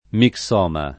mixoma [ mik S0 ma ] s. m. (med.); pl. ‑mi